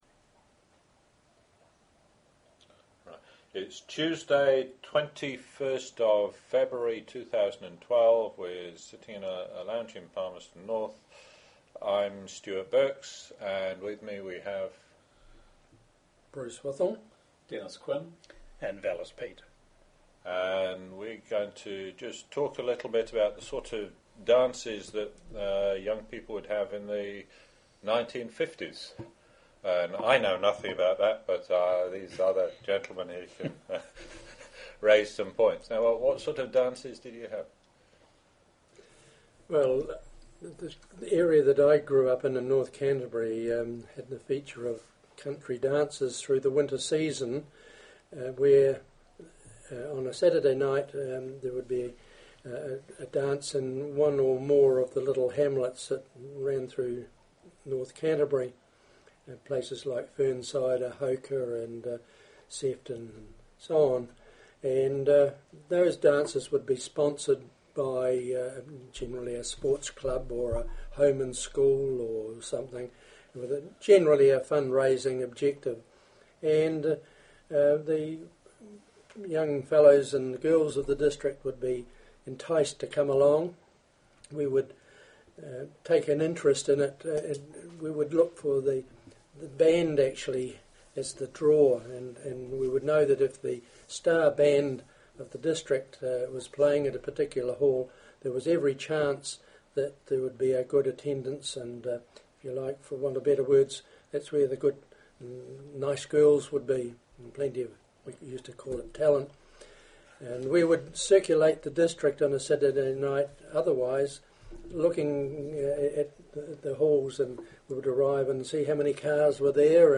Country Dances - oral interview - Manawatū Heritage